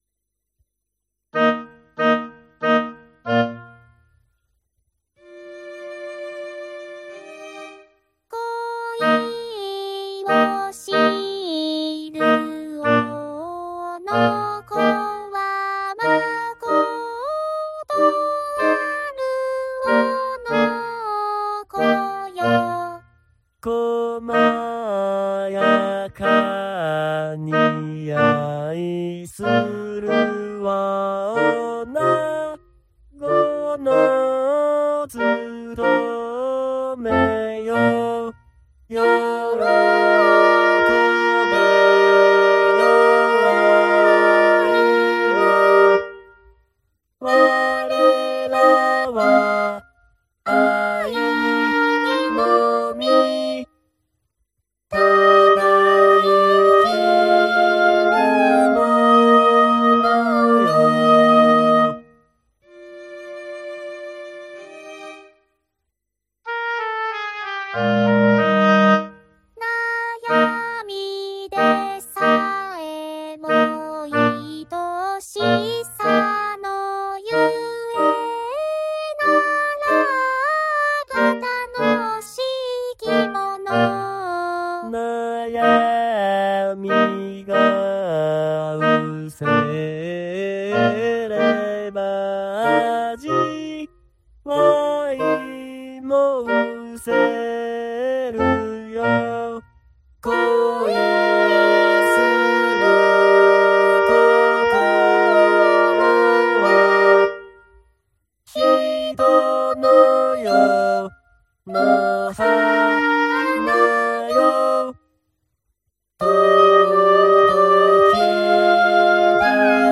05/03/04 音量が全曲を通して弱かったので、強くなるよう訂正しました。
演奏時間 作成日 MIDI  Vocaloidで日本語の音声を入れ、管弦楽はHalion Symphonic Orchestra(VST)を使ってMP3形式で保存したものです。